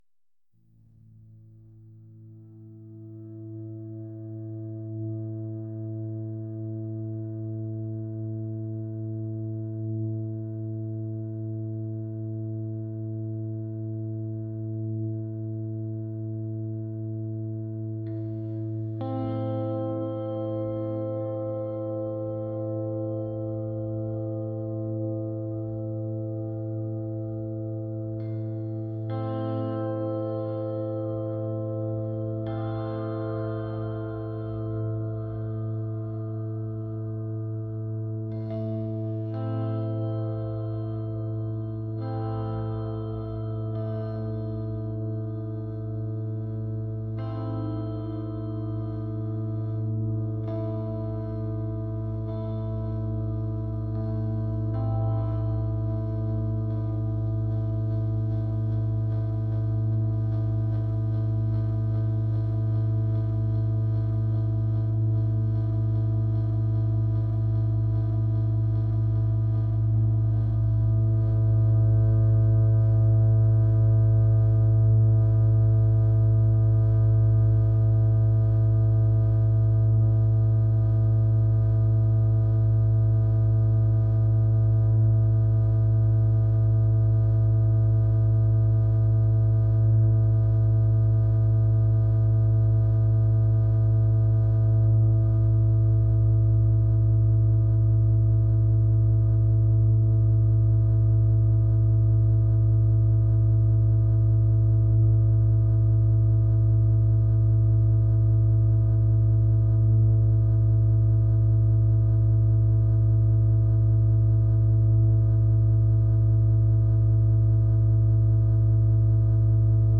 atmospheric